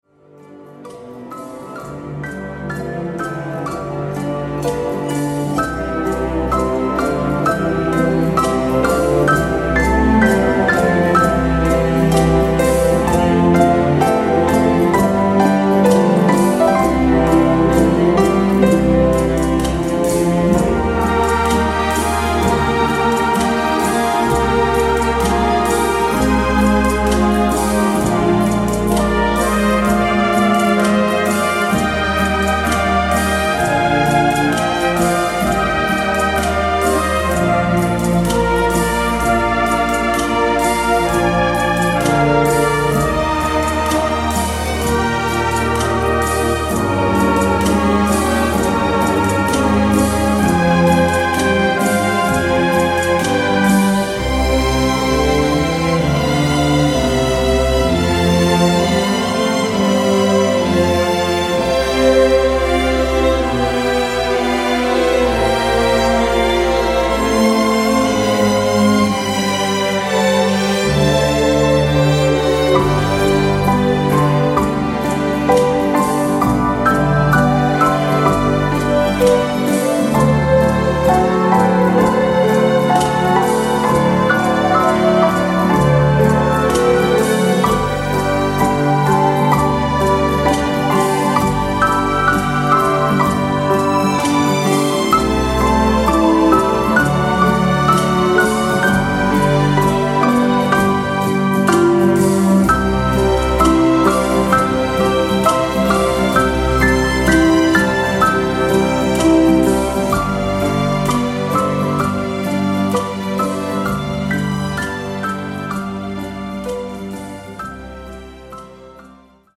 Classical Slow Beat